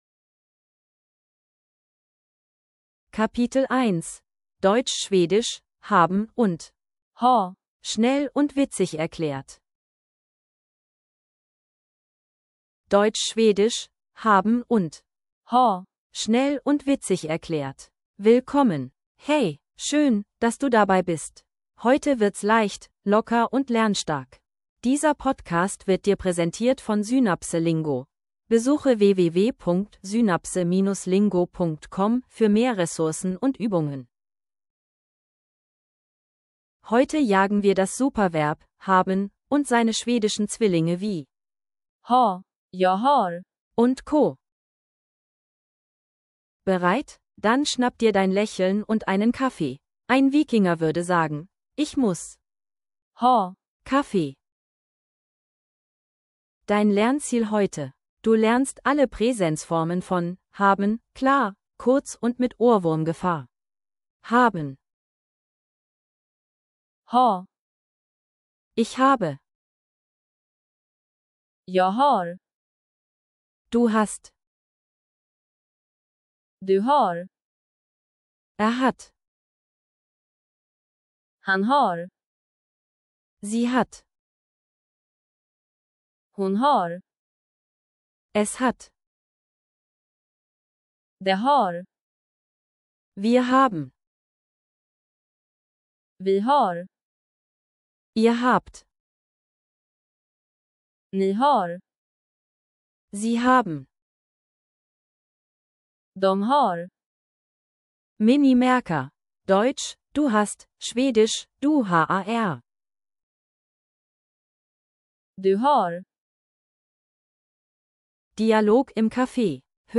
Audio zum Mitsprechen & Wiederholen